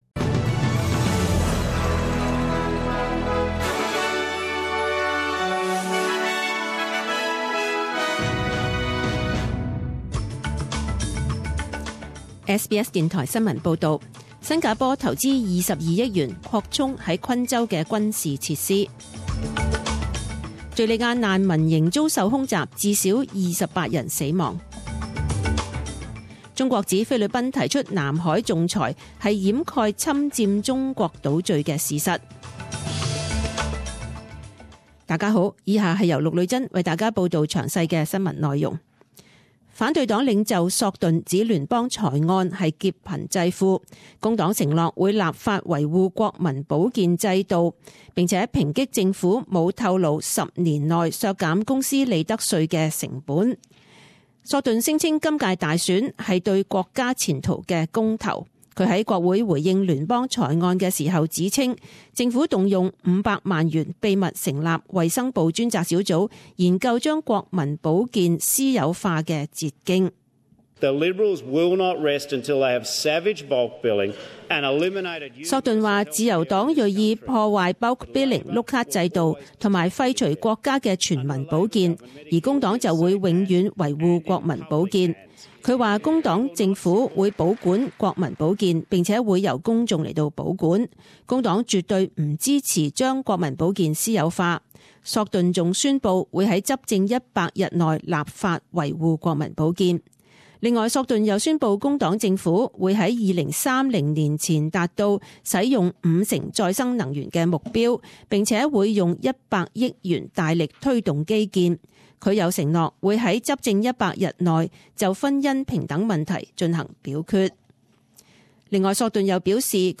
十点钟新闻报导 （五月六日）